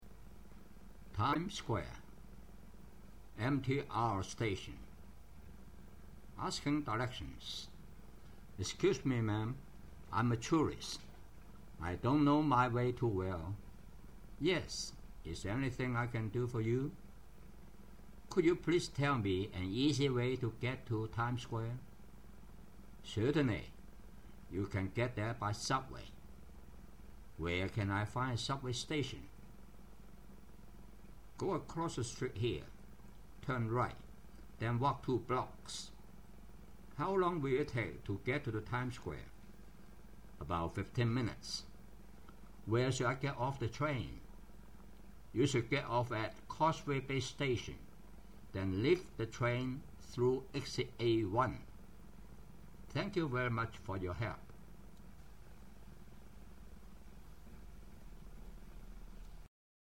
讀音
asking-directions.mp3